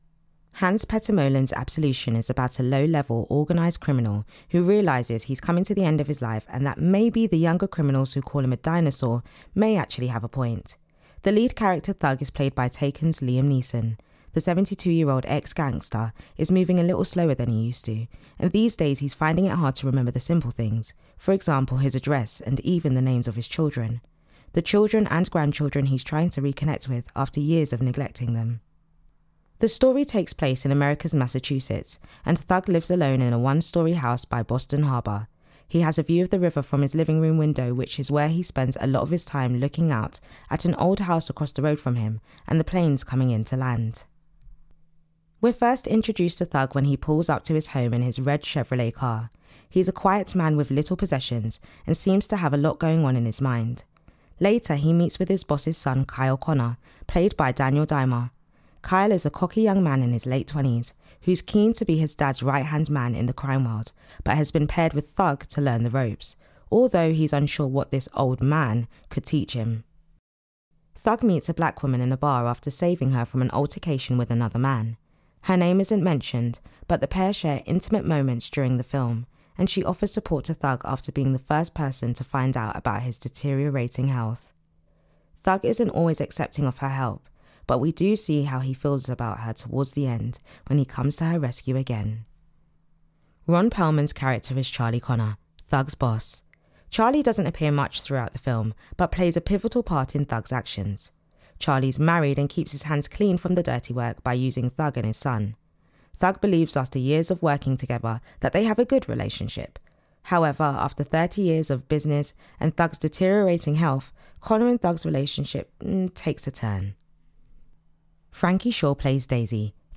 Absolution - AD Introduction